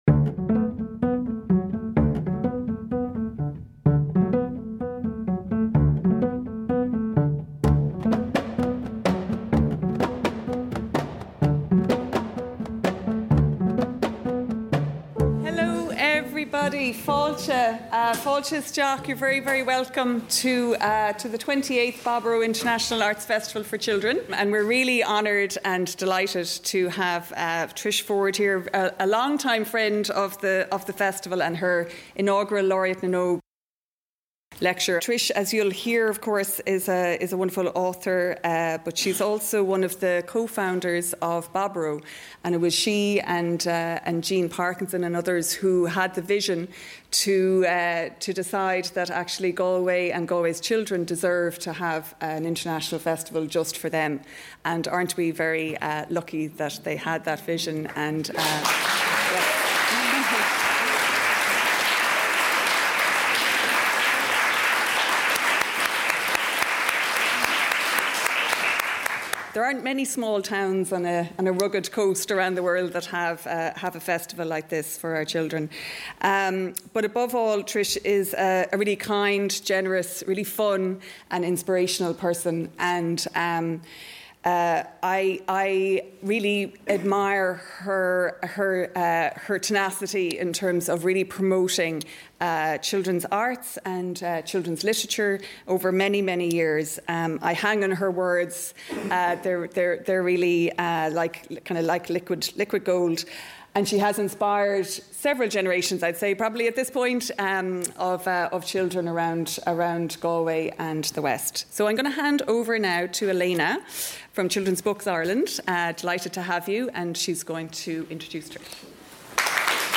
Inaugural Lecture
Recorded on Friday, 18 October 2024 during Baboró International Arts Festival for Children, Galway.